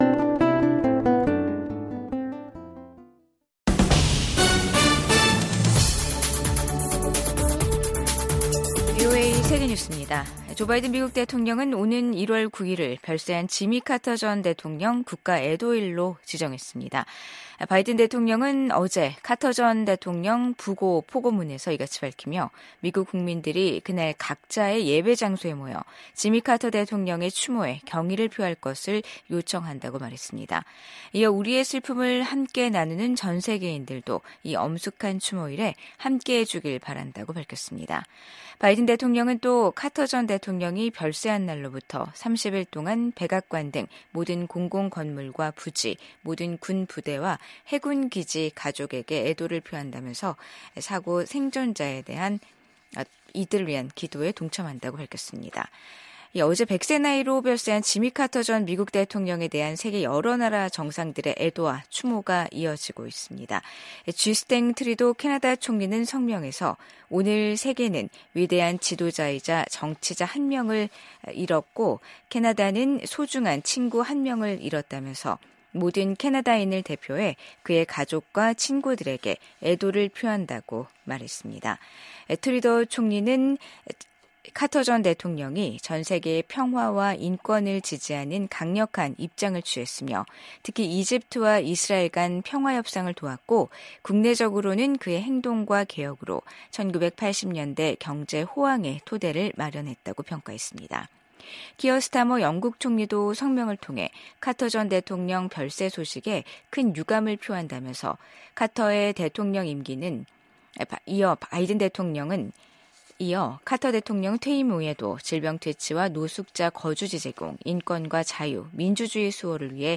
VOA 한국어 간판 뉴스 프로그램 '뉴스 투데이', 2024년 12월 30일 2부 방송입니다. 김정은 북한 국무위원장은 연말 노동당 전원회의에서 미국에 대해 최강경 대응전략을 천명했지만 구체적인 내용은 밝히지 않았습니다. 러시아에 파병된 북한군이 무모한 인해전술을 펼치면서 지난주에만 1천명이 넘는 사상자가 발생했다고 미국 백악관이 밝혔습니다. 미국 국무부는 한국의 새 대통령 대행과도 협력할 준비가 돼 있다고 밝혔습니다.